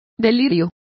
Complete with pronunciation of the translation of delusions.